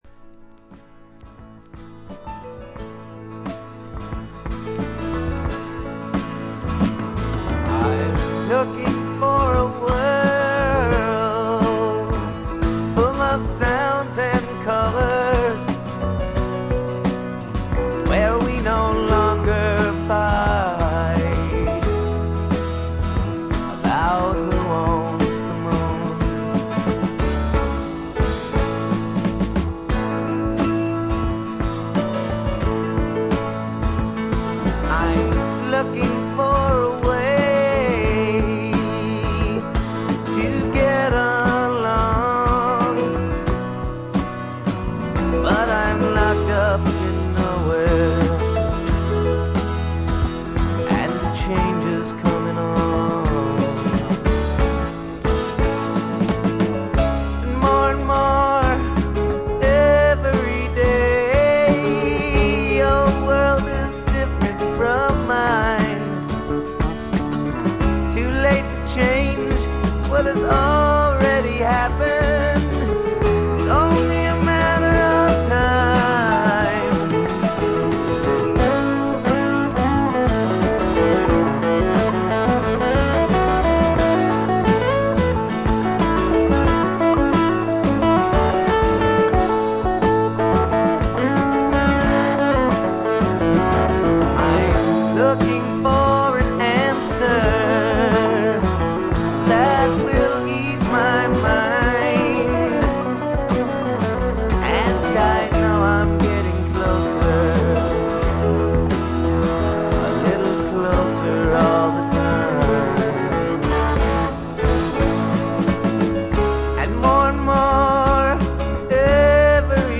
わずかなノイズ感のみ。
渋みのあるヴォーカルも味わい深い88点作品。
試聴曲は現品からの取り込み音源です。